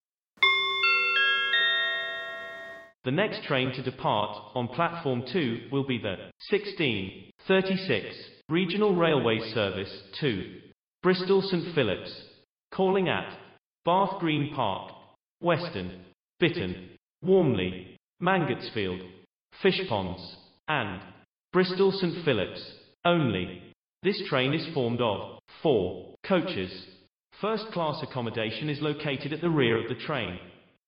Train Departure Announcement Generator BR and Soundscaper
• 3 types of BR intro chimes or none
• Based on the Phil Sayer announcements used throughout the network